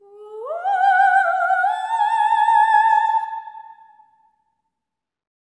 OPERATIC02-L.wav